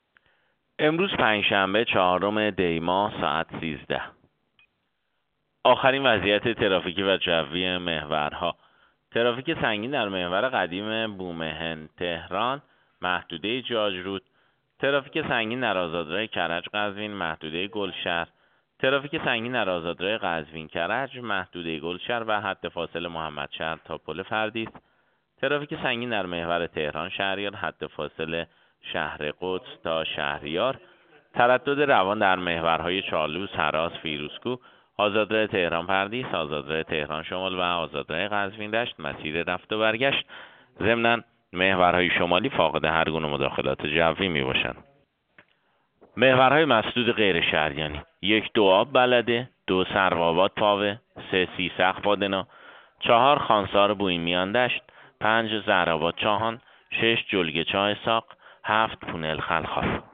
گزارش رادیو اینترنتی از آخرین وضعیت ترافیکی جاده‌ها ساعت ۱۳ چهارم دی؛